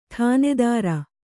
♪ ṭhānedāra